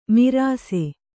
♪ mirāse